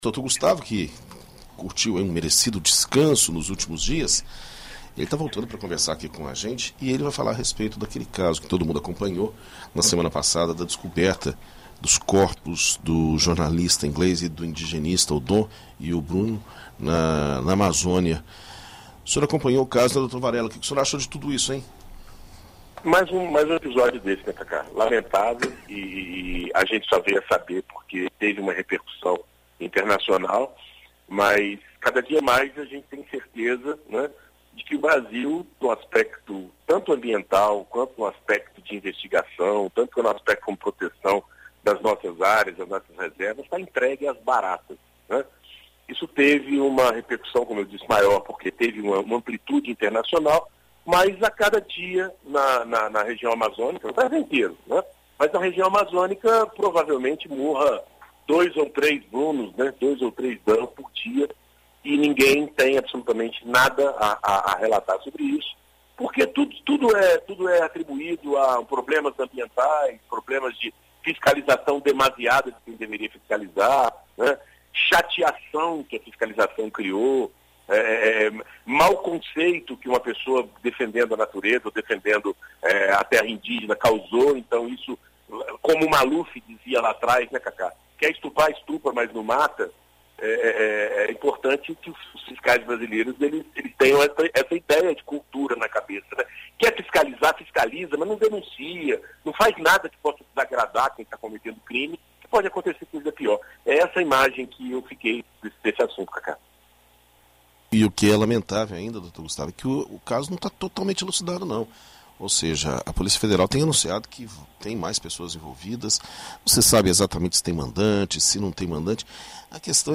Na coluna Direito para Todos desta segunda-feira (20), na BandNews FM Espírito Santo